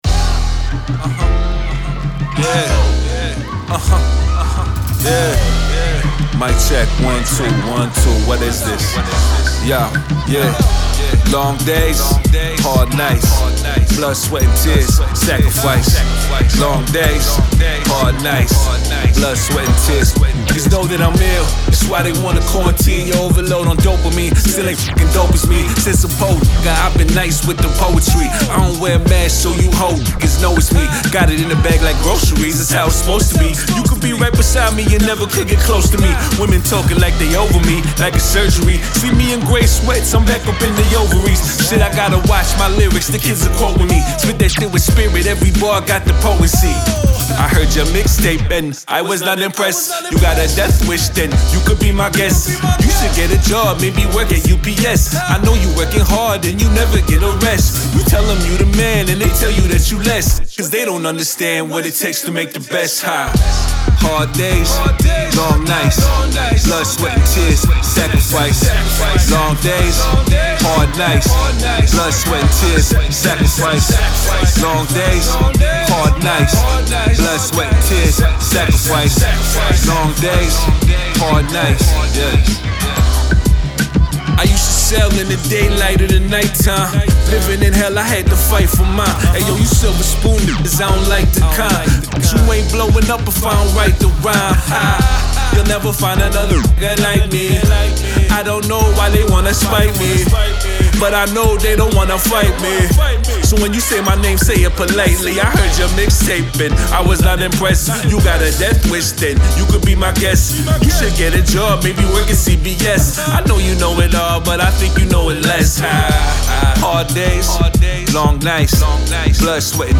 Description : Classic Canadian Hip Hop.